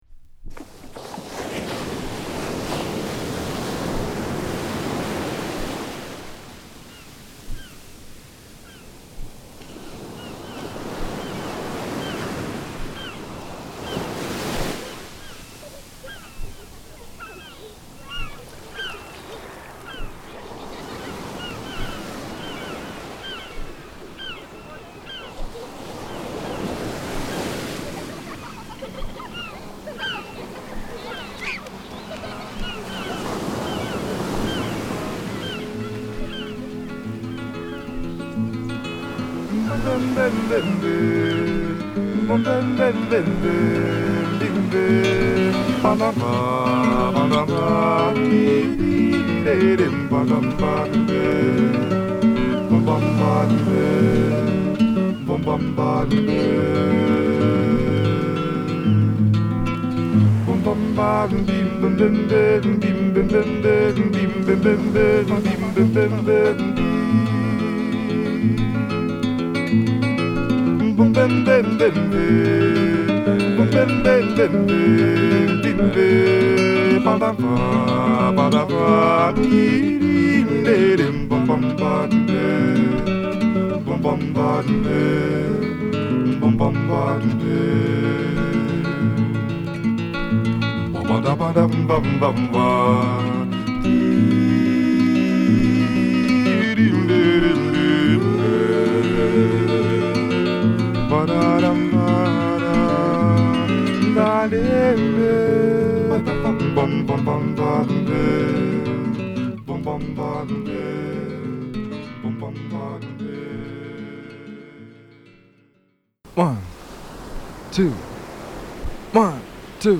アルバム通してメロウながら躍動感に溢れたフォーキーな曲を満載